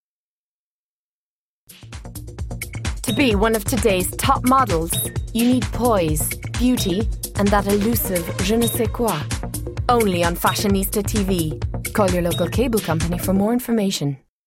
I have a bright friendly voice that can also be quite serious but all the while dynamic and interesting.
Sprechprobe: Sonstiges (Muttersprache):